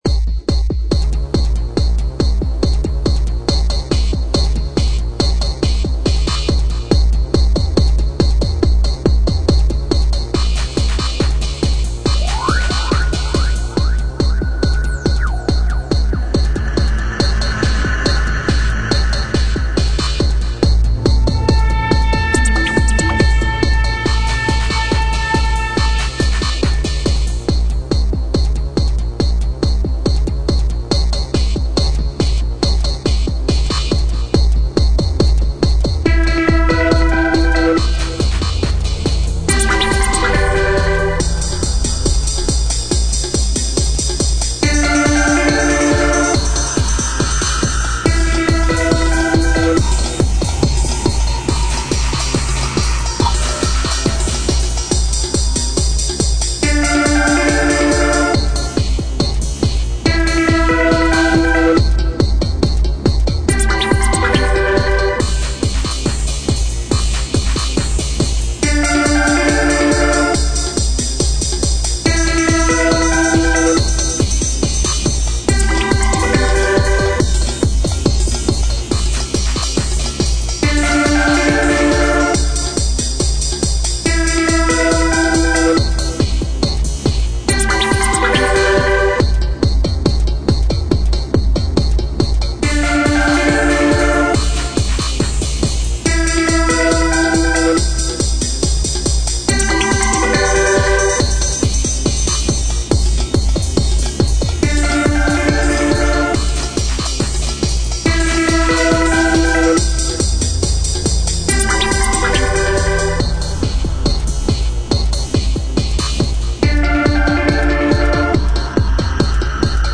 • Жанр: Электронная
instrumental